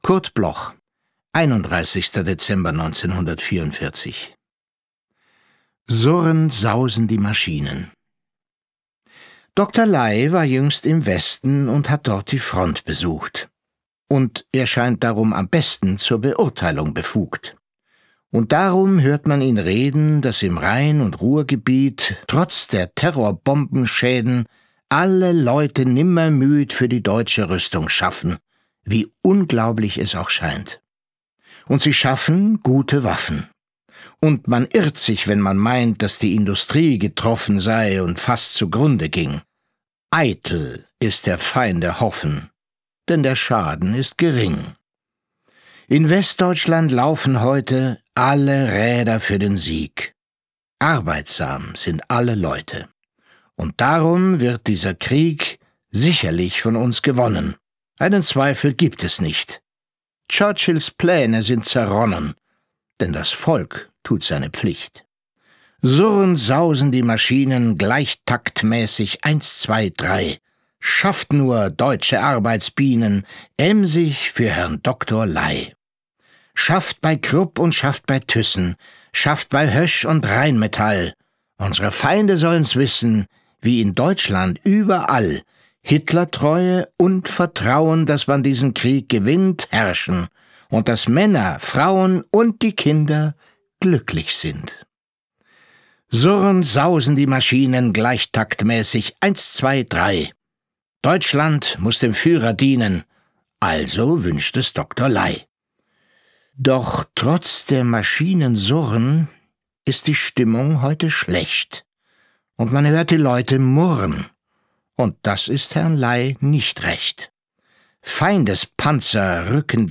Recording: speak low, Berlin · Editing: Kristen & Schmidt, Wiesbaden